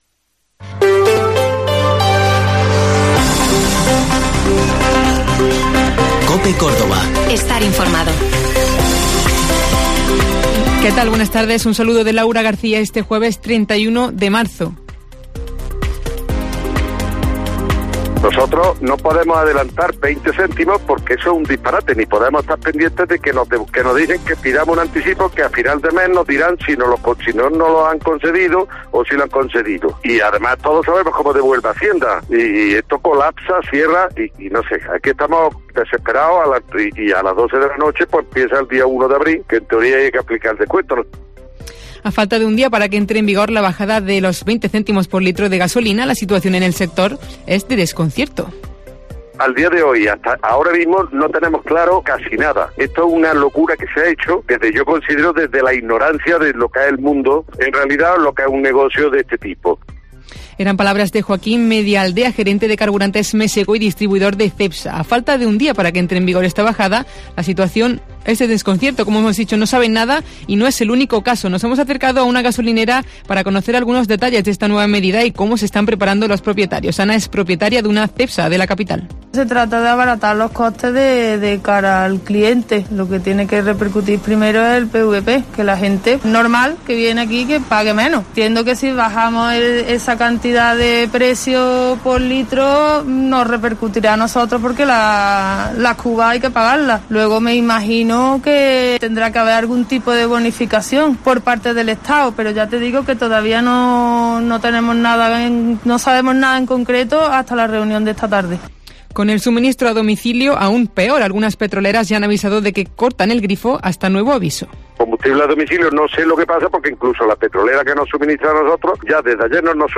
Informativo Mediodía